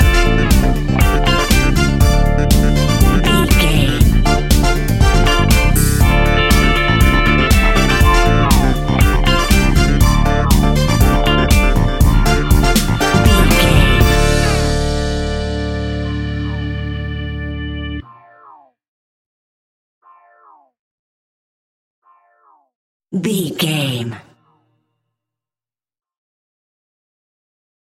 Aeolian/Minor
D
funky
groovy
uplifting
driving
energetic
strings
brass
bass guitar
electric guitar
electric organ
synthesiser
drums
funky house
disco house
electro funk
upbeat
synth leads
Synth Pads
synth bass
drum machines